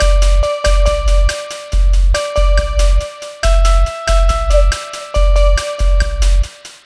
Cheese Lik 140-D.wav